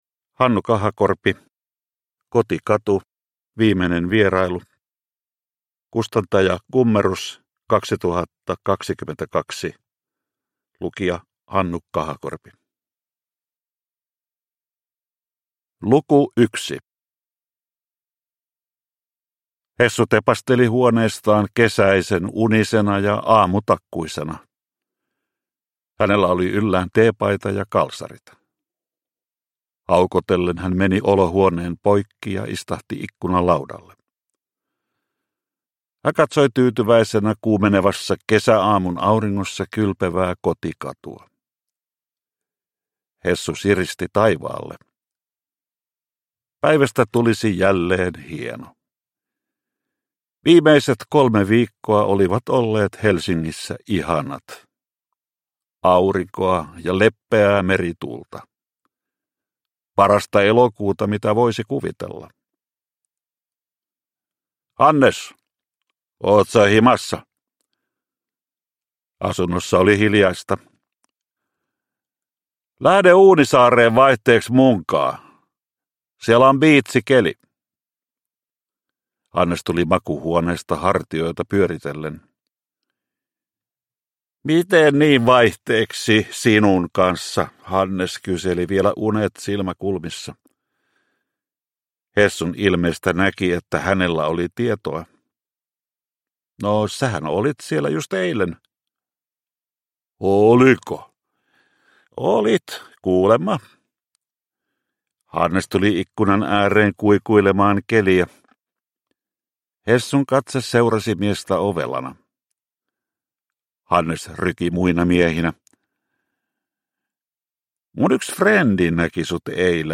Kotikatu - Viimeinen vierailu – Ljudbok – Laddas ner